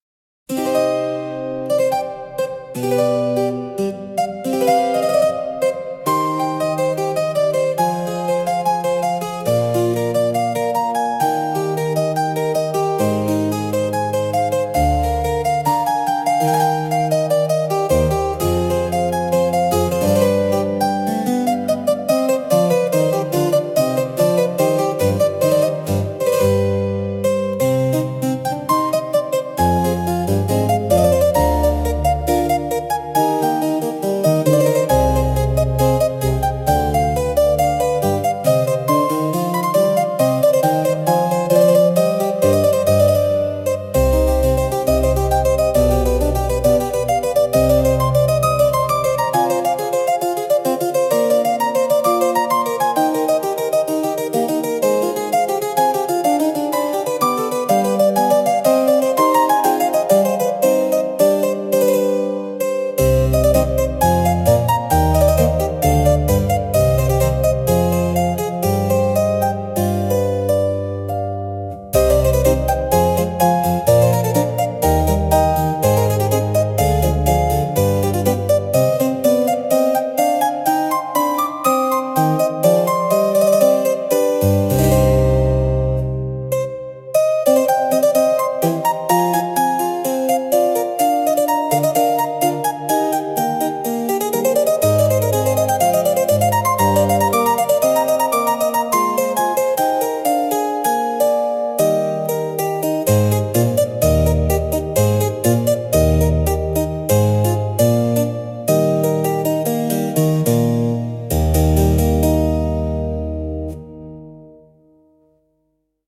When Suno is given was given this prompt, “Solo Harpsichord, Baroque, J.S. Bach style, Goldberg Variations, Aria, contrapuntal, elegant, ornate, sophisticated, 18th-century keyboard music, intricate ornamentation, trills, G Major, 3/4 time, reflective, serene, high-quality recording” This is what it generated with that prompt.
It's very much in the style of Bach and Baroque music.
Goldberg-Reverie-in-G.mp3